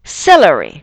celery [seləri]
celery.wav